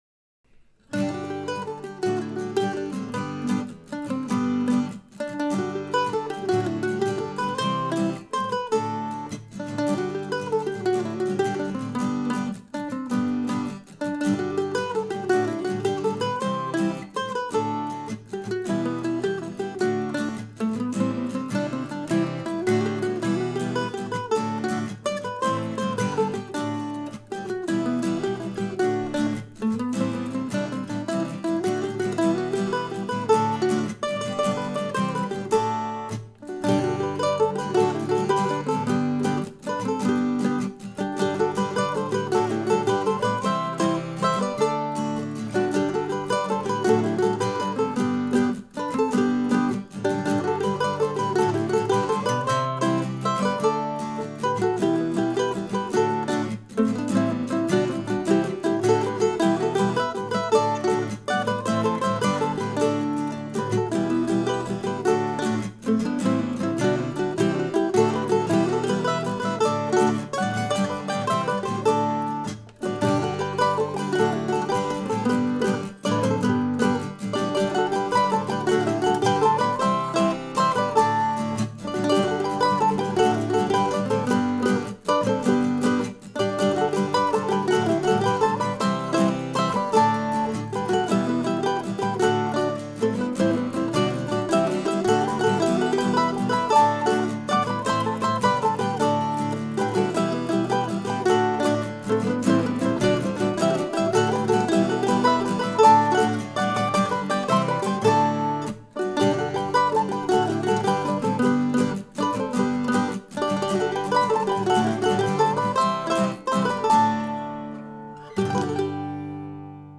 In any event I think the tune here is a fun jig to play. The recording has some rhythmic quirks, partly because (I'm not sure why) I recorded the guitar last.
This meant I had to struggle a little more than usual to get the succeeding tracks to end in the same neighborhood.